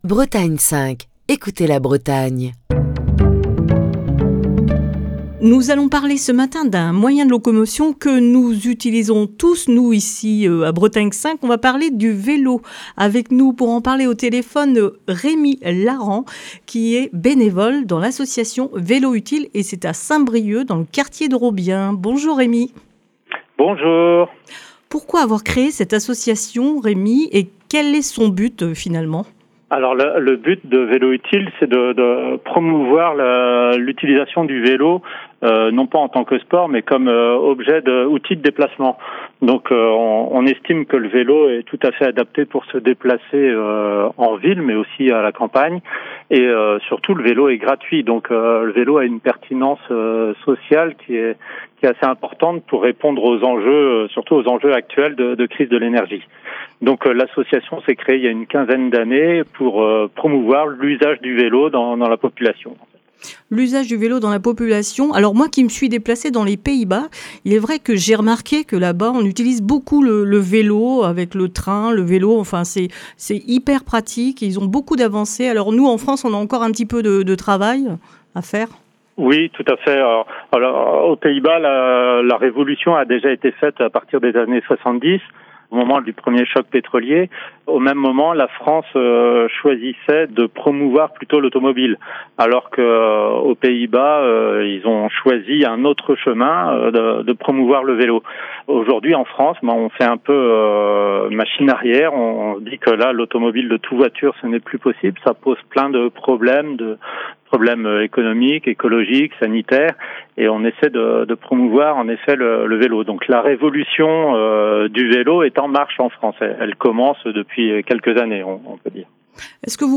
Ce lundi, dans le coup de fil du matin,